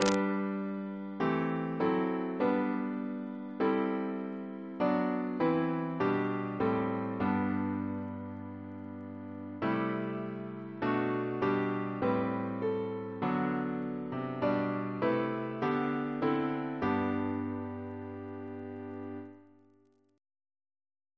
Double chant in G Composer